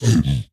zpigangry2.mp3